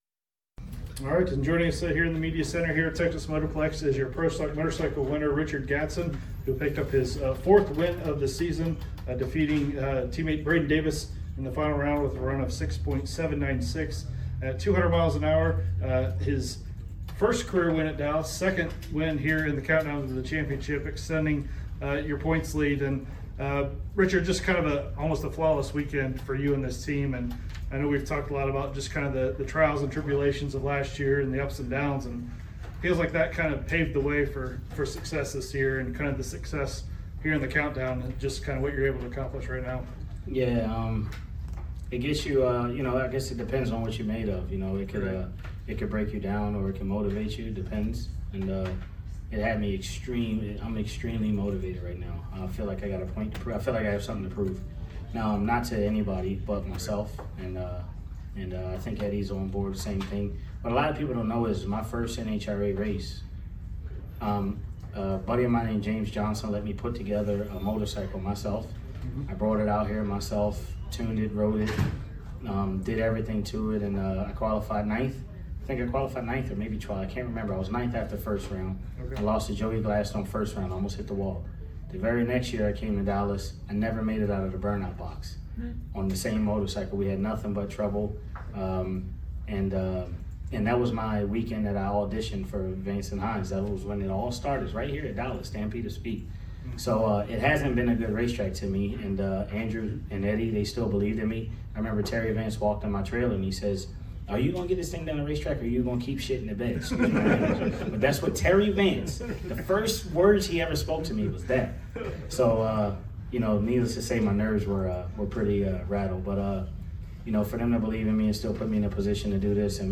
Media Center Interview: